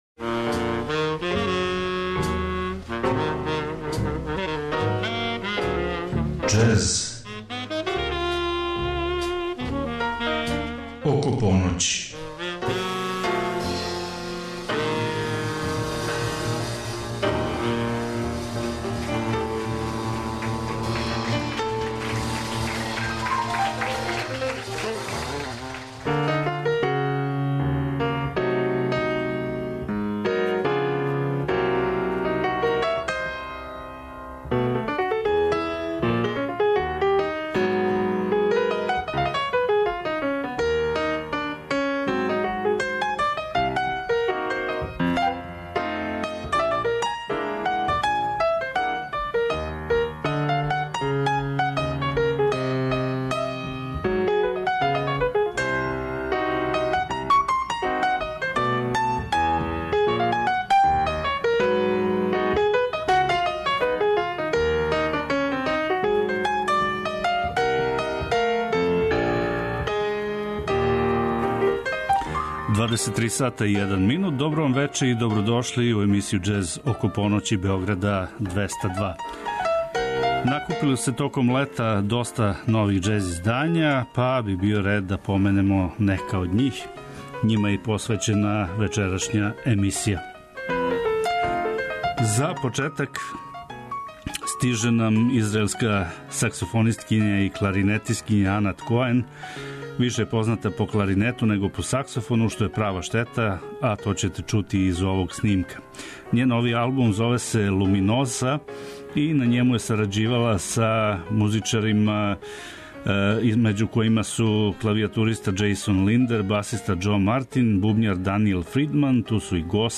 Емисија је магазинског типа и покрива све правце џез музике, од Њу Орлиенса, преко мејнстрима, до авангардних истраживања. Теме су разноврсне - нова издања, легендарни извођачи, снимци са концерата и џез клубова, архивски снимци...